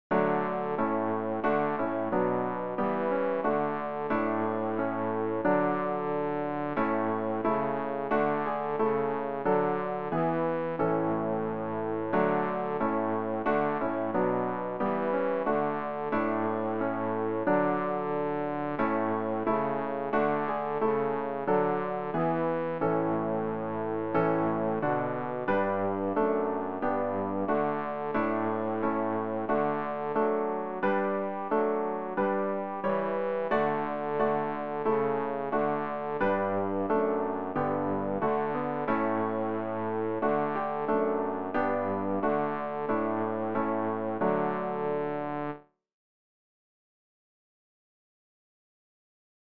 rg-444-jesu-meines-lebens-leben-bass.mp3